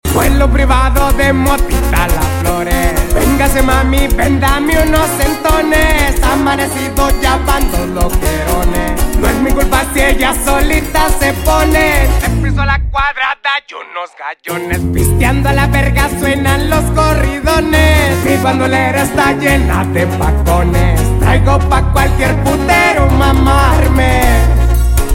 2024 Ford Mustang Shelby Supersnake